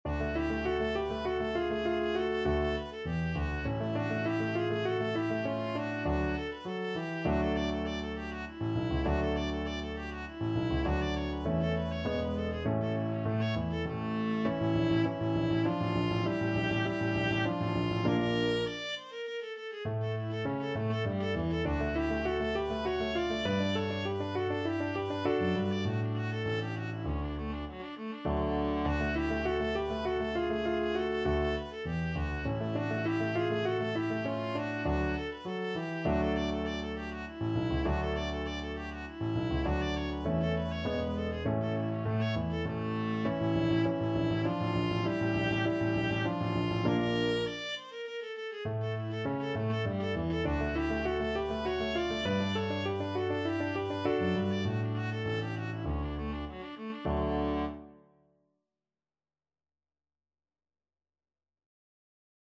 Viola
D major (Sounding Pitch) (View more D major Music for Viola )
3/4 (View more 3/4 Music)
Classical (View more Classical Viola Music)
paganini_minuetto_VLA.mp3